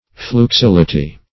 Fluxility \Flux*il"i*ty\, n.